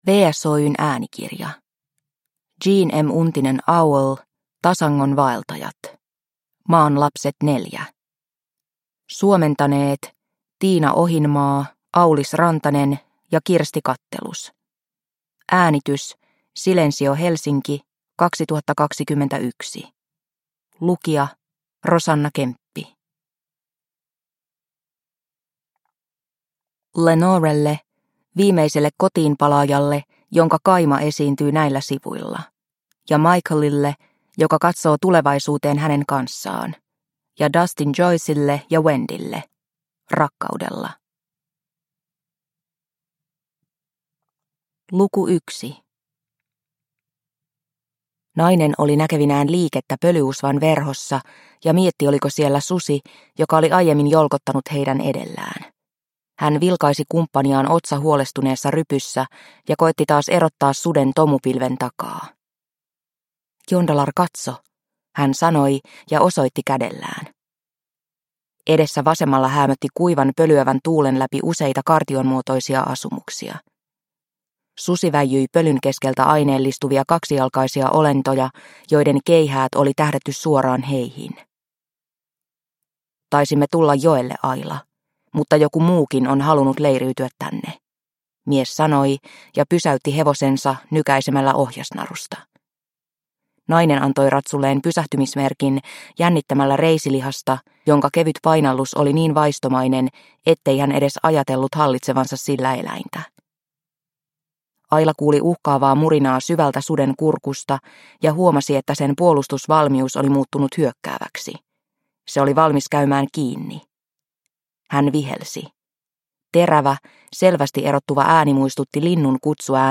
Tasangon vaeltajat – Ljudbok – Laddas ner